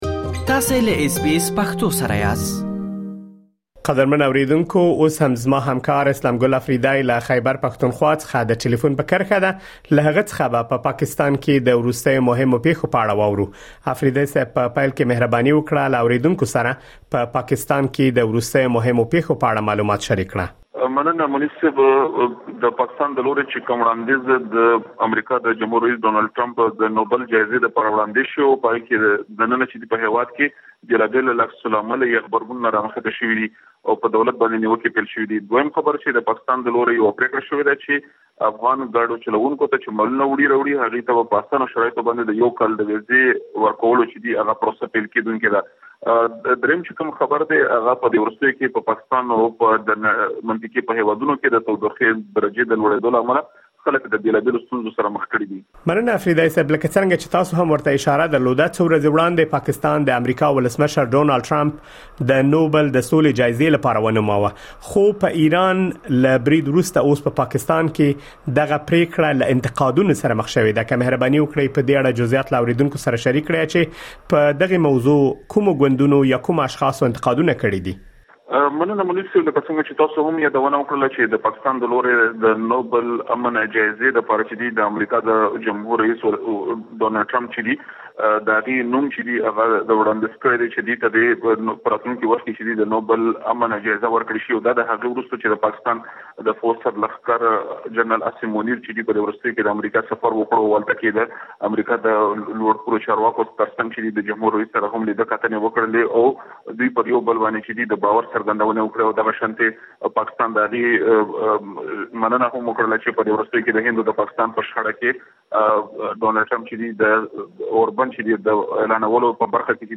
په مرکې کې د لاندې مهمو پېښو په اړه معلومات اورېدلی شئ: د پاکستان له حکومت څخه غوښتل شوي چې د نوبل جايزې لپاره د ټرمپ د نوماندۍ وړانديز بېرته واخلي د پاکستان حکومت افغان موټر چلوونکو ته په ۲۴ ساعتونو کې ویزې ورکوي. پاکستان کې د تودوخې کچه لوړه شوې او خلک ستونزو سره مخ دي.